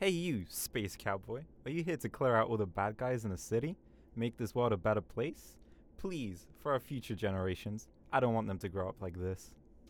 Street barklines
hey you space cowboy are you here to.wav